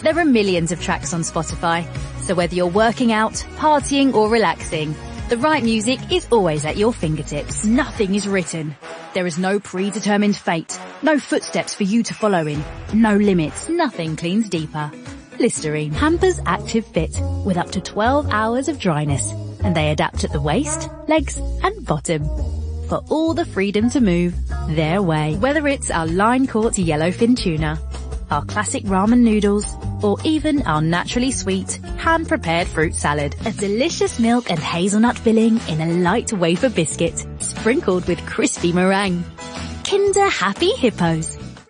London, Friendly, Chatty & Trusting
Commercial Reel